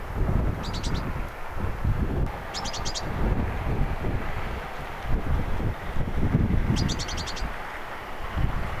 erikoinen huomioääni talitiaislinnulla
Missähän on talitiaisilla näin erikoinen huomioääni?
erikoinen_huomioaani_talitiaislinnulla.mp3